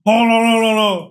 PLAY 轟隆隆隆隆隆
Play, download and share 轟隆隆隆隆隆 original sound button!!!!